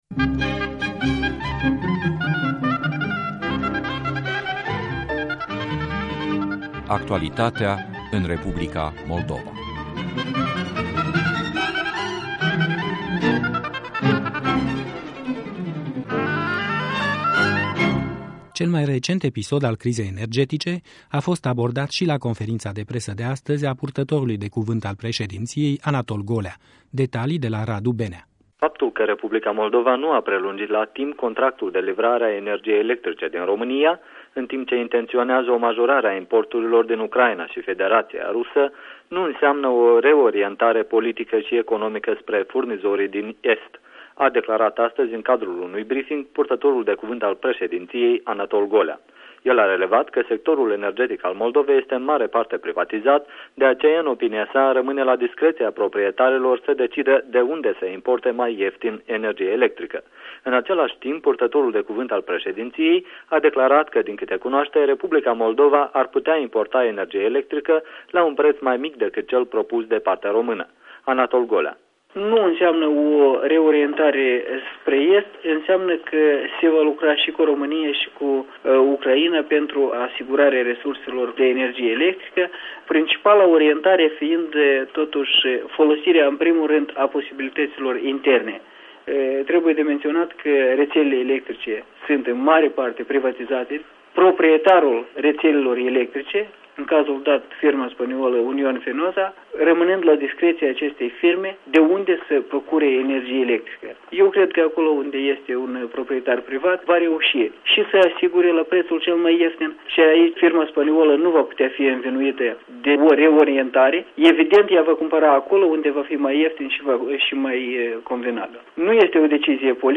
Conferință de presă pe tema importurilor de energie